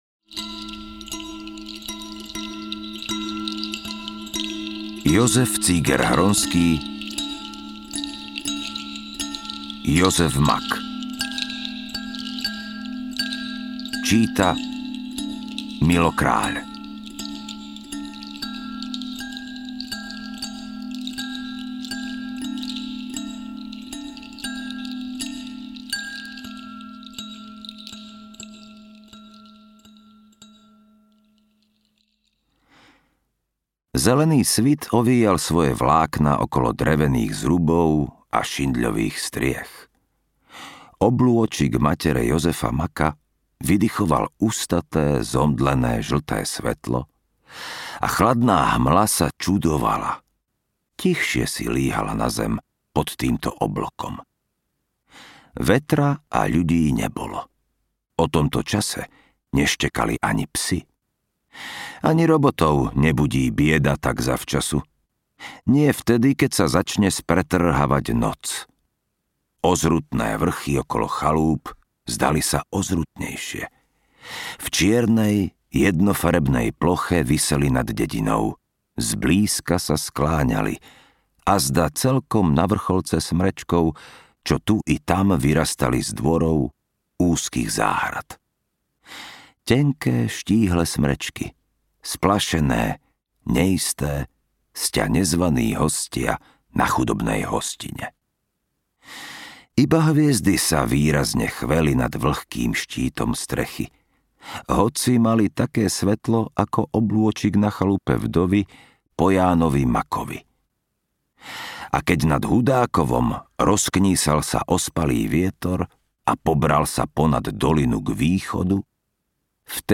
Jozef Mak audiokniha
Ukázka z knihy